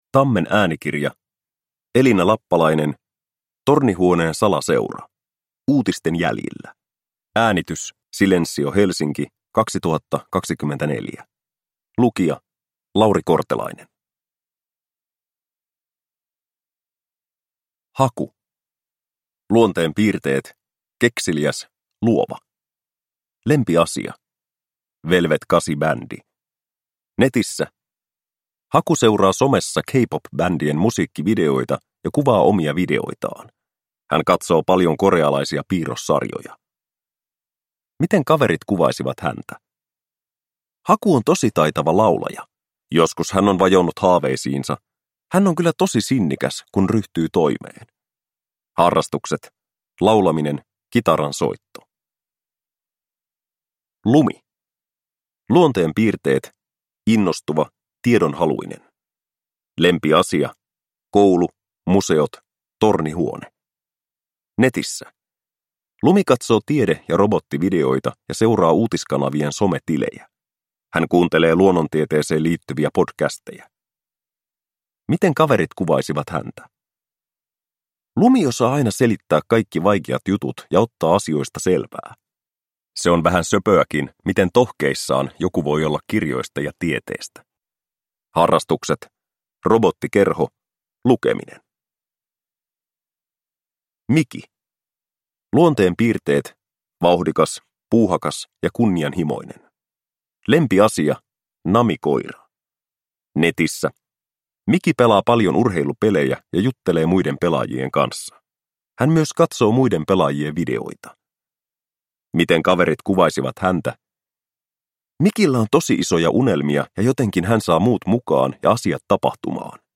Tornihuoneen salaseura. Uutisten jäljillä – Ljudbok
• Ljudbok